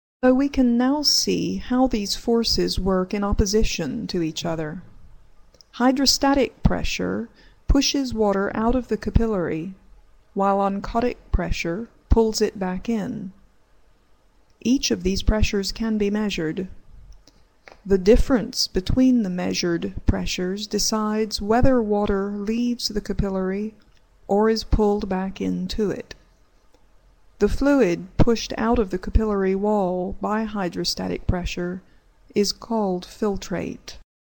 Narration audio